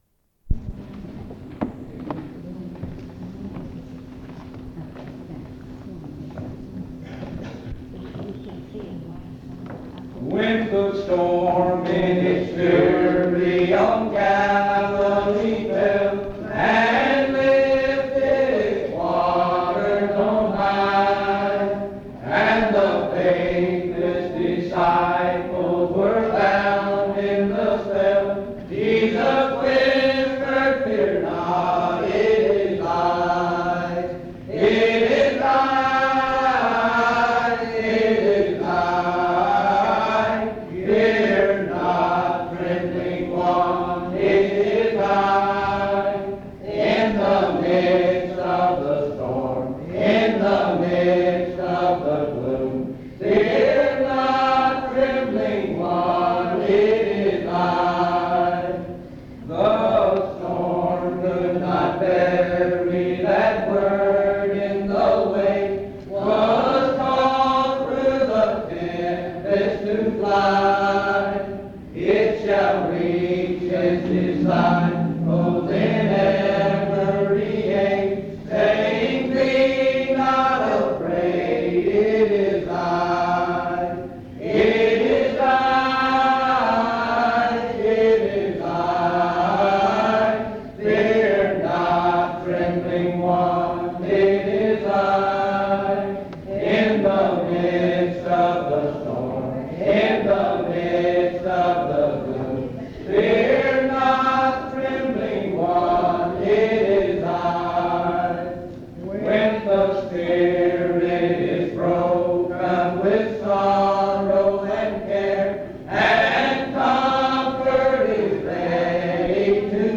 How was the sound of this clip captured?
Location Rockingham County (N.C.) Reidsville (N.C.)